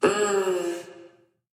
Girl Crush K-Pop Vocals by VOX
VOX_GCK_vocal_oneshot_impact_wet_mm_sexy_Fm
mmhh-girl-crush.mp3